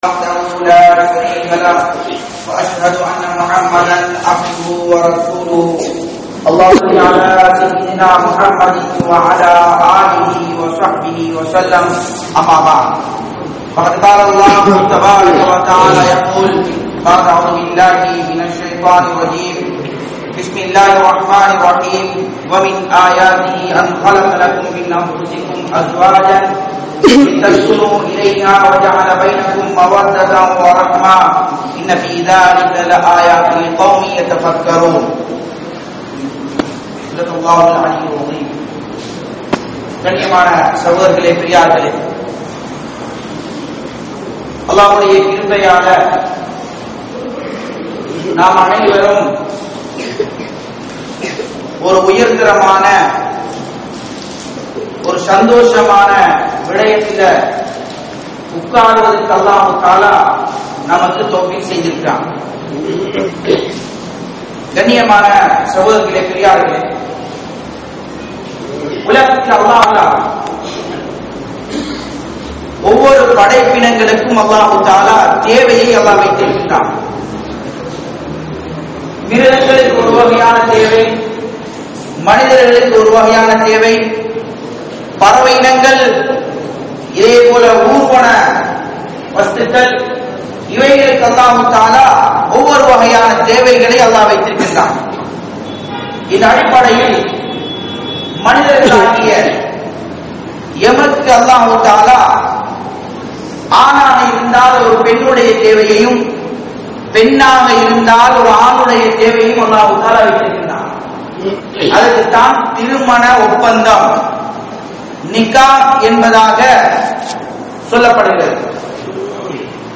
Kudumba Vaalkaiel Santhoasam veanduma? (குடும்ப வாழ்க்கையில் சந்தோசம் வேண்டுமா??) | Audio Bayans | All Ceylon Muslim Youth Community | Addalaichenai
Warakapola Jumua Masjidh